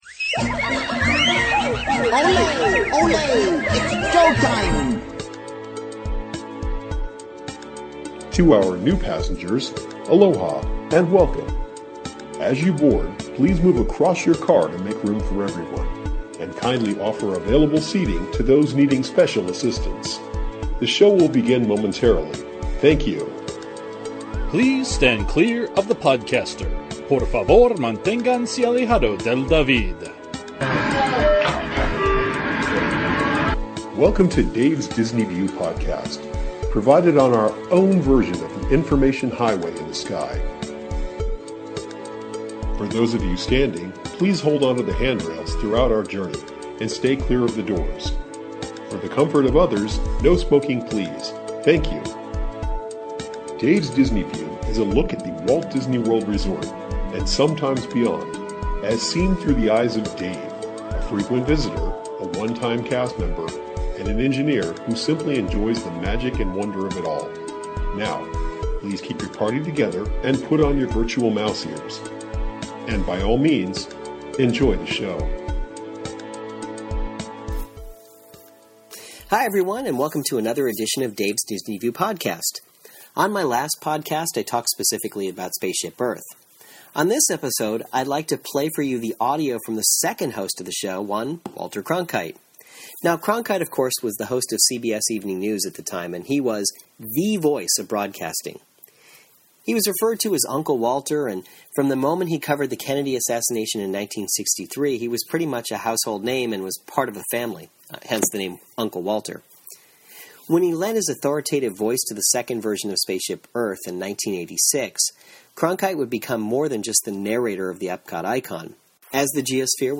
Respected as a journalist, Cronkite adds a certain authority to the audio. On this show, its Walter's narration of SpaceShip Earth.